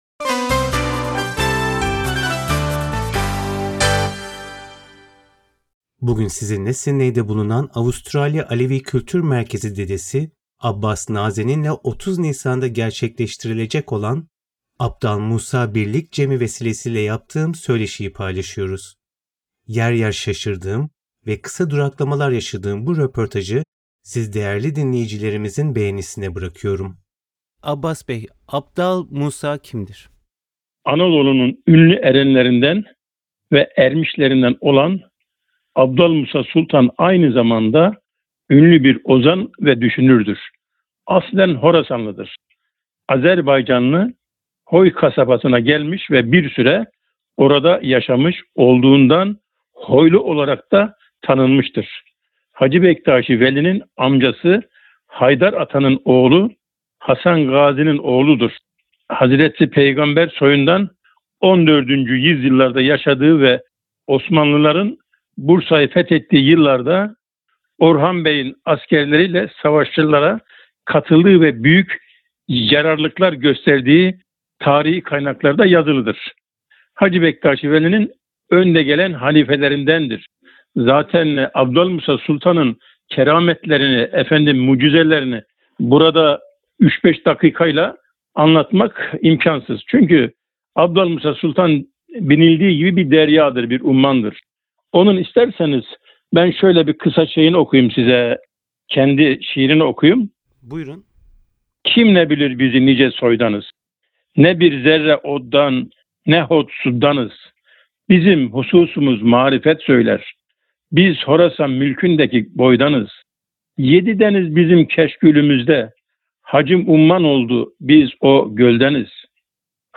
bilgilendirici bir söyleşi